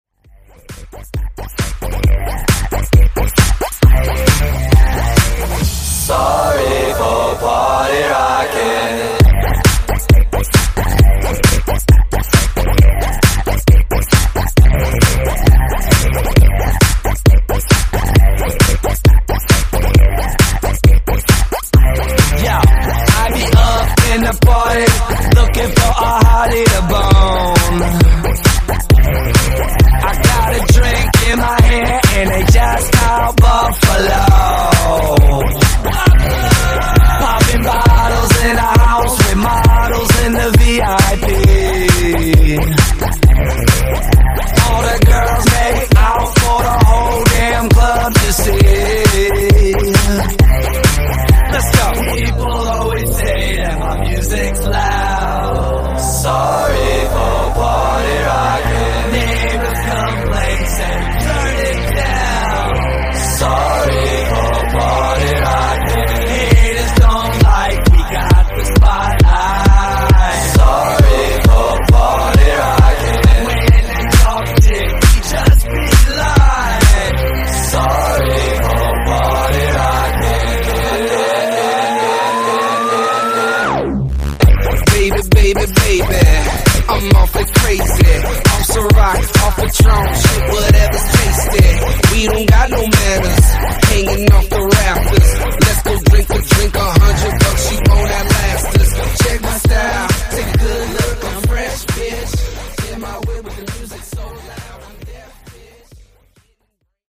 Genre: RE-DRUM Version: Dirty BPM: 68 Time